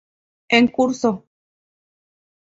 Pronunciado como (IPA) /en ˈkuɾso/